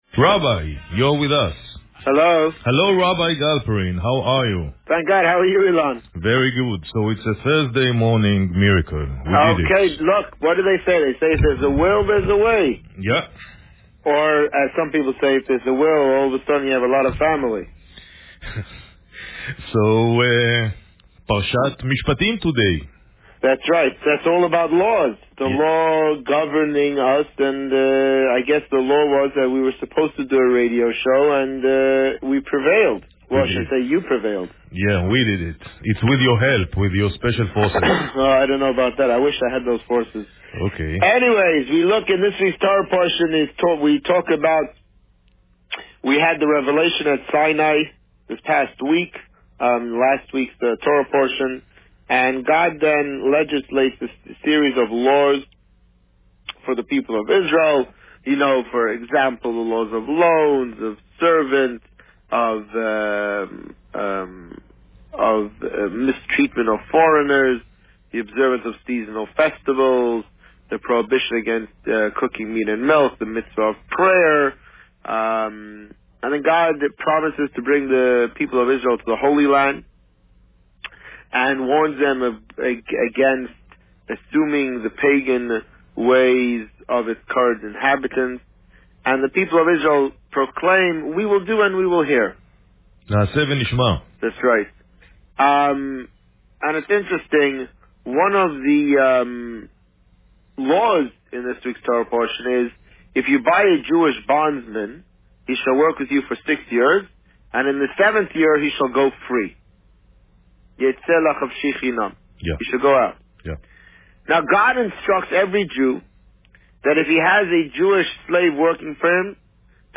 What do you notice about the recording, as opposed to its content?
The Rabbi on Radio